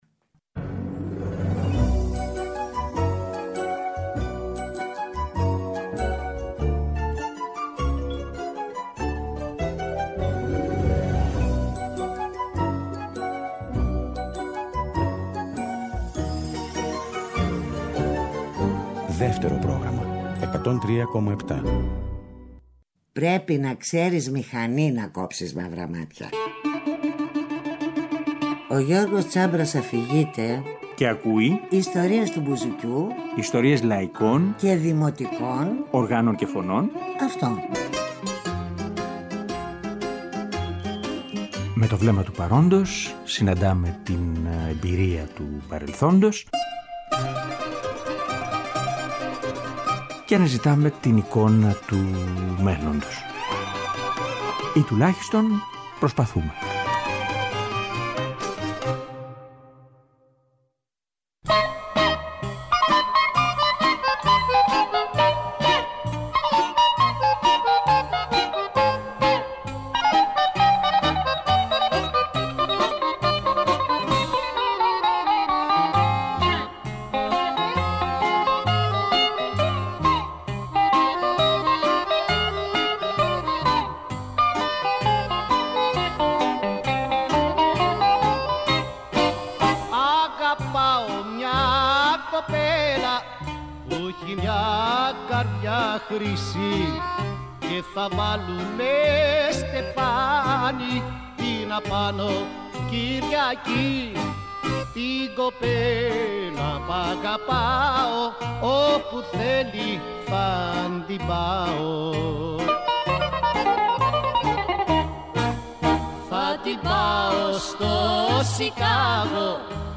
τσάμικο